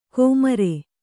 ♪ kōmare